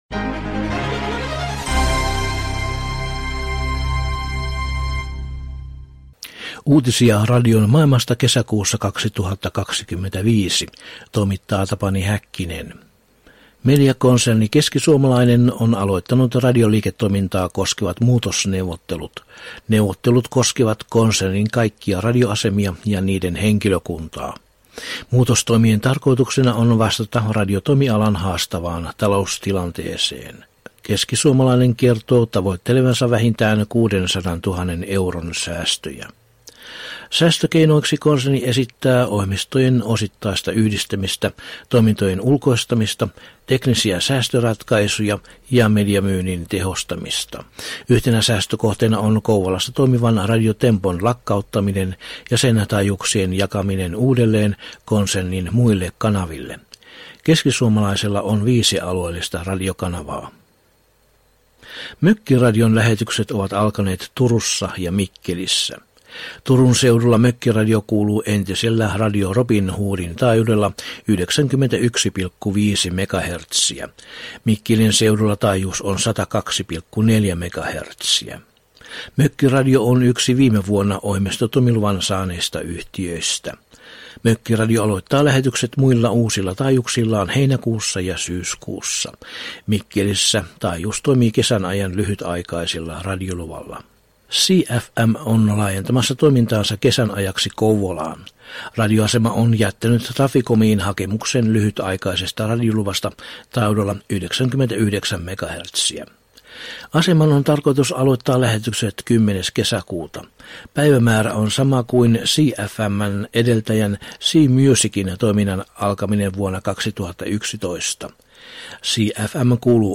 Kesäkuun 2025 uutislähetyksessä aiheina ovat muun muassa Keskisuomalaisen radioliiketoiminnassa käynnistetyt muutosneuvottelut, Mökkiradion FM-lähetysten aloitus ja keskiaaltotoimiluvan hakeminen Norjassa.